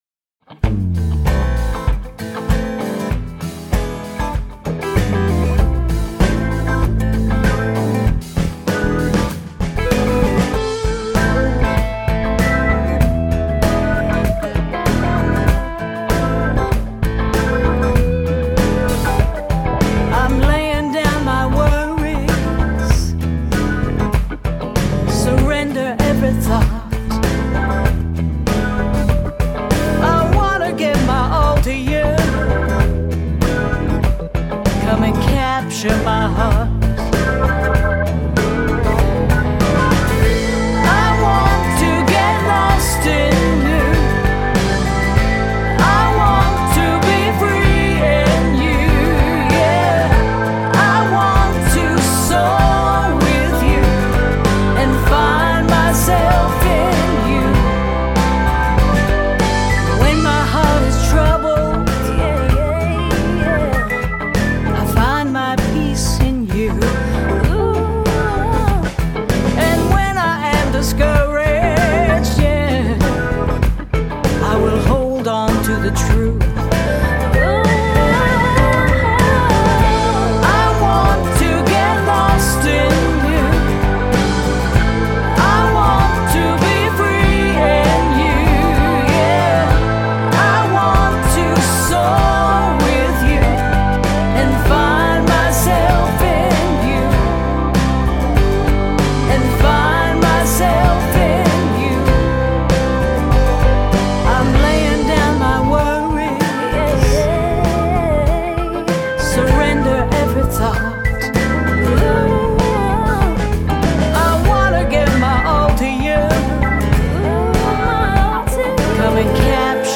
It’s a combination of solo and congregational worship songs.